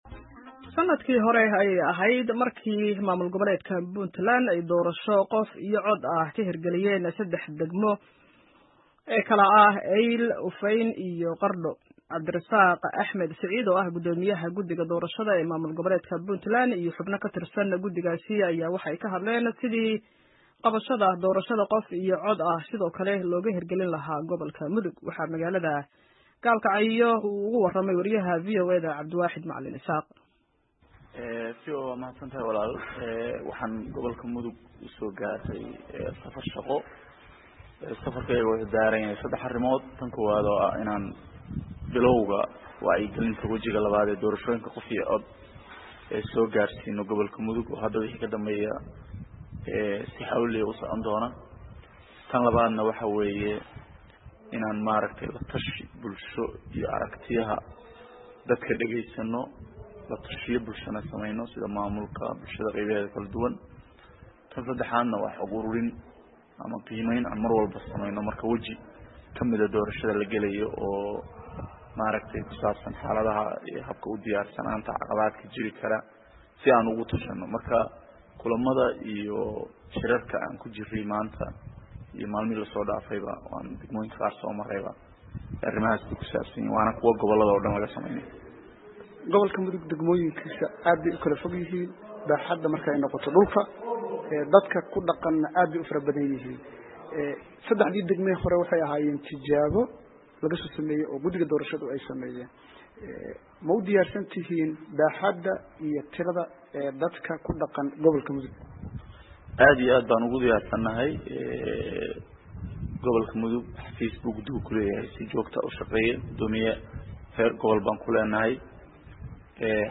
Sanadkii hore ayay ahayd markii maamul goboleedka Puntland ay doorashao qof iyo cod ah ka hirgeliyeen saddexda degmo ee Eyl, Ufeyn iyo Qardho, waxaana Cabdirisaaq Axmed Siciid oo ah Guddoomiyaha guddiga doorashada ee Puntland iyo xubno ka tirsan guddigaas ayaa ka hadlay sidii qabashada doorashada qof iyo cod ah sidoo kale looga hirgelin lahaa gobolka Mudug.